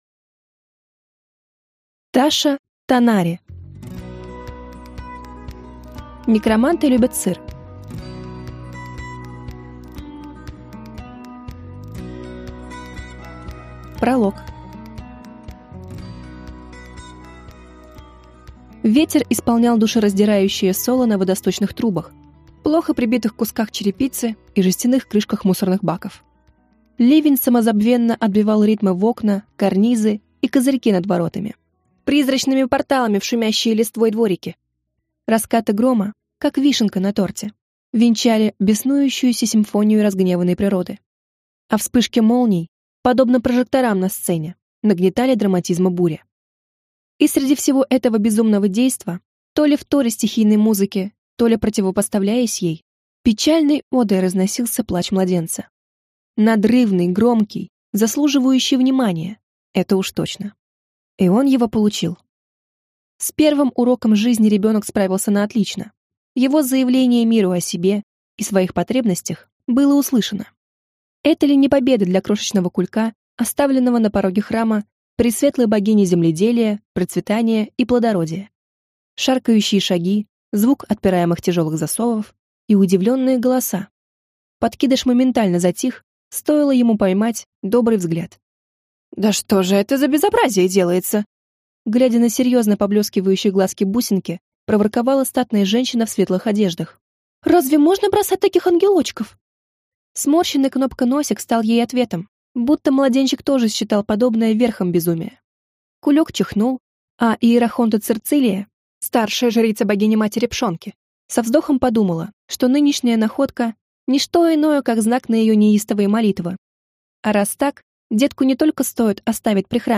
Аудиокнига Некроманты любят сыр | Библиотека аудиокниг
Прослушать и бесплатно скачать фрагмент аудиокниги